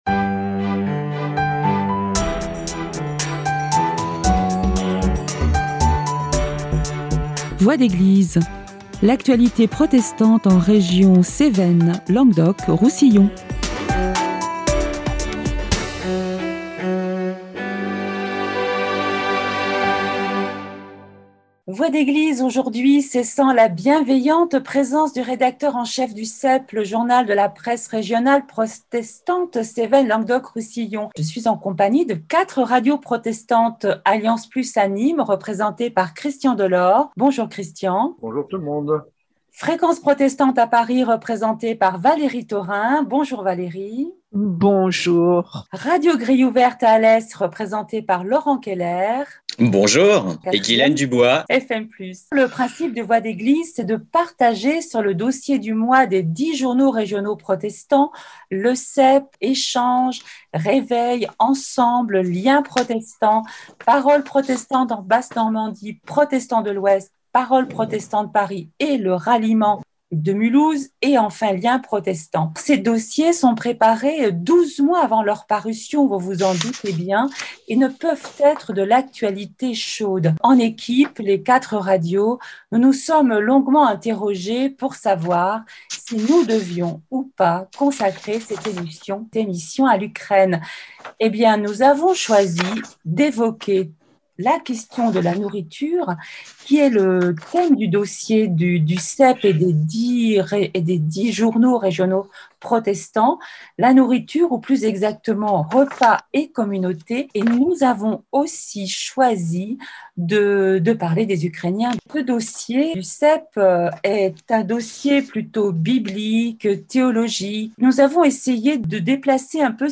animent une discussion autour du dossier des journaux régionaux protestants, les repas.